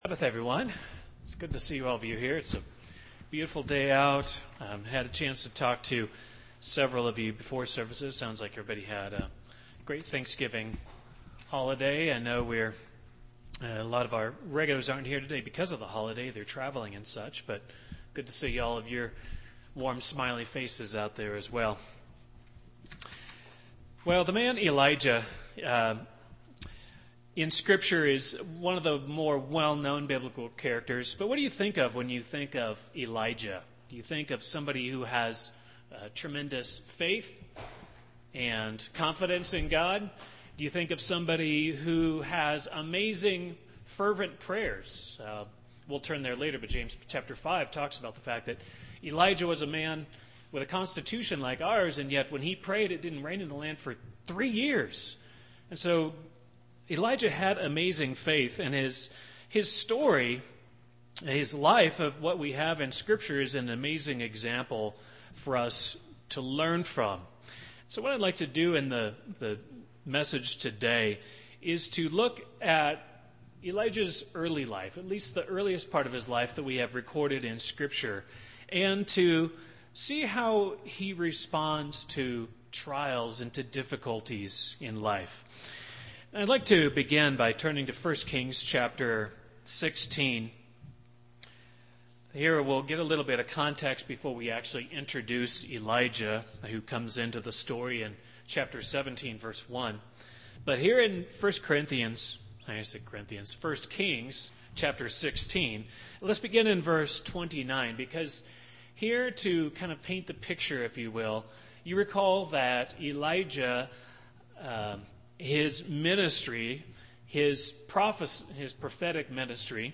Sermons
Given in Loveland, CO